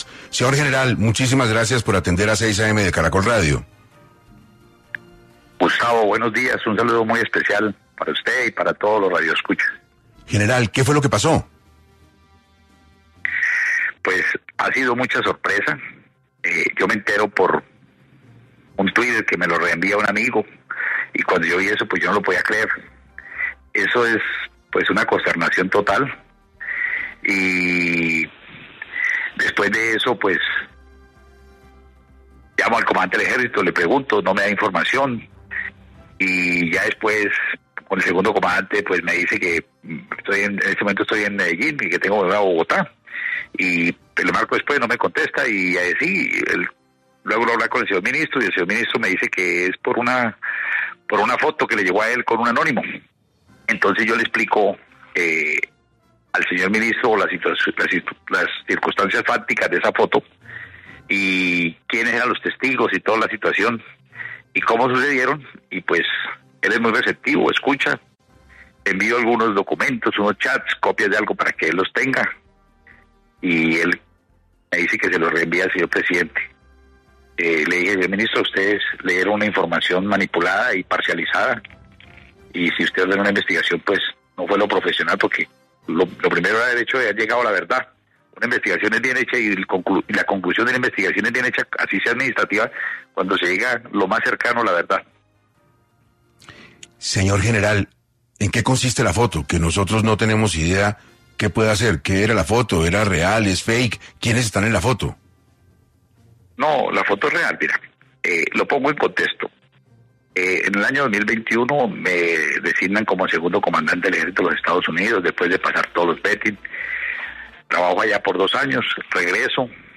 El inspector General Hernando Garzón Rey, de las Fuerzas Militares se conectó en el programa de 6AM de Caracol Radio para hablar sobre las razones detrás de la acusación de Petro sobre nexos con el narcotráfico.